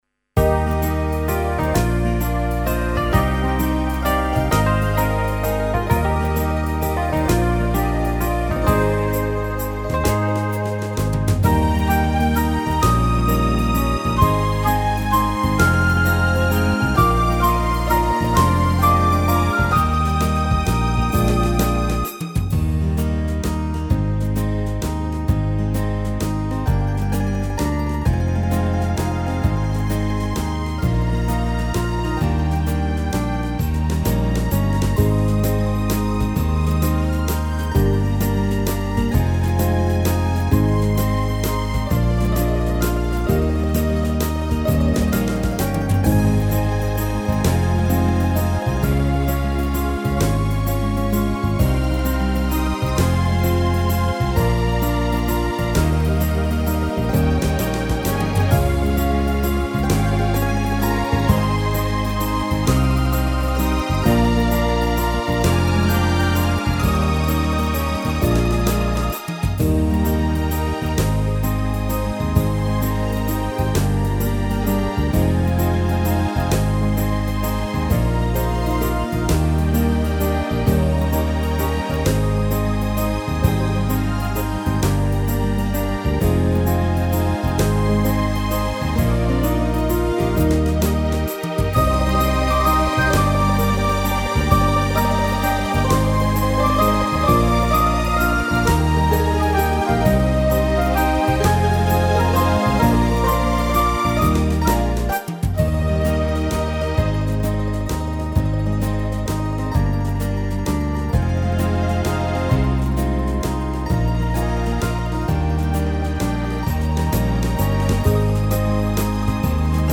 •   Beat  01.
(D#) 3:07